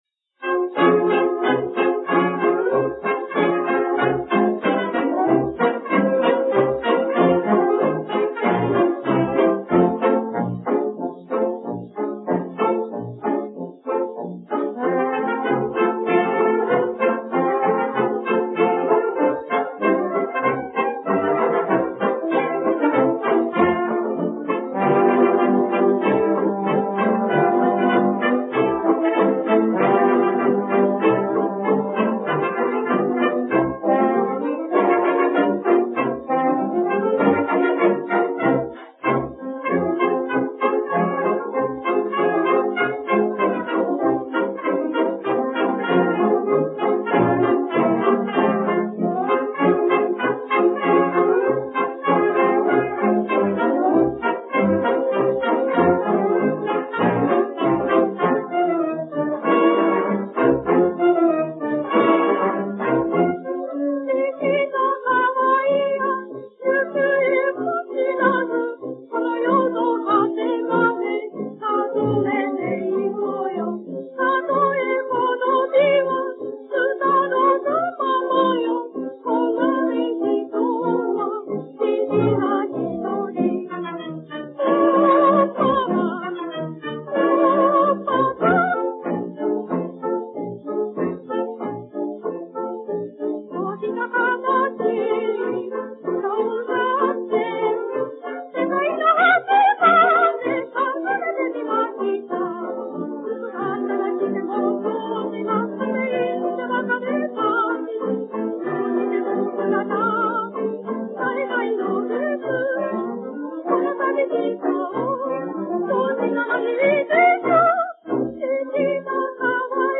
да ещё и с такой безысходностью в голосе